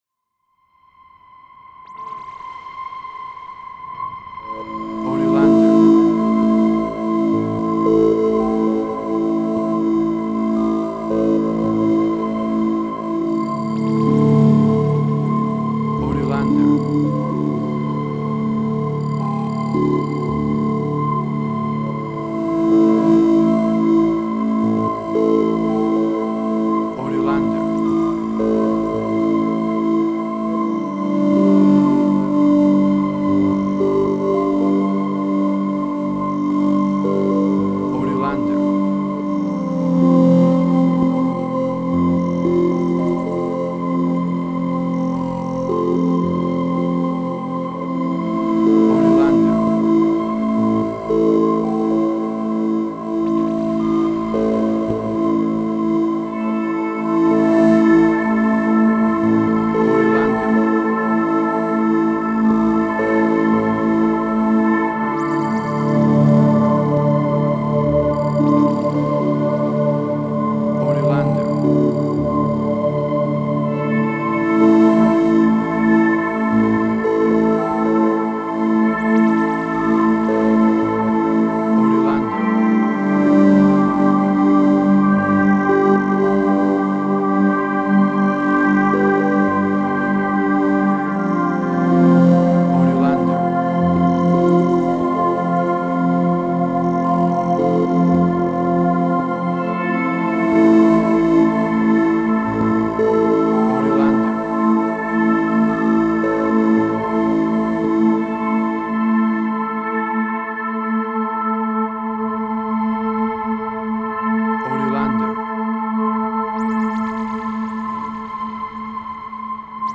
Ambient Strange&Weird,.
emotional music
WAV Sample Rate: 16-Bit stereo, 44.1 kHz